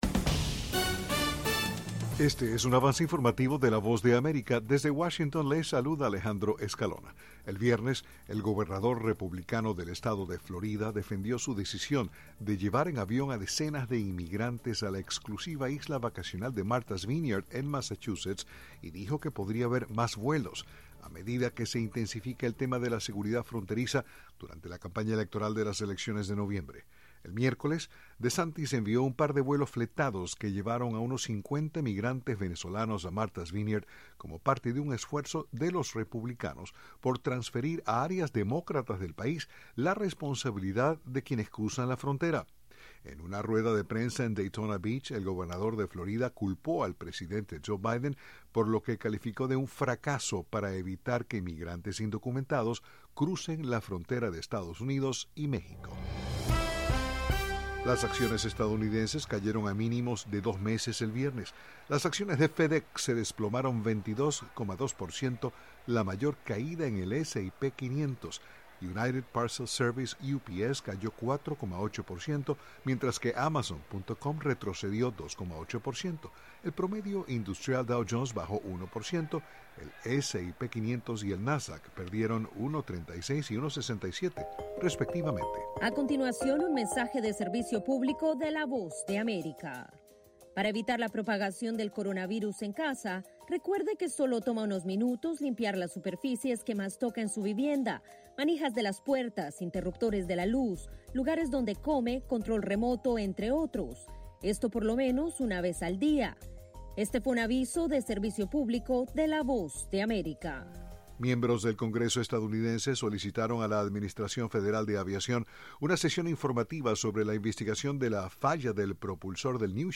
Avance Informativo
Este es un avance informativo presentado por la Voz de América en Washington.